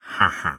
Minecraft Version Minecraft Version 1.21.4 Latest Release | Latest Snapshot 1.21.4 / assets / minecraft / sounds / mob / illusion_illager / idle3.ogg Compare With Compare With Latest Release | Latest Snapshot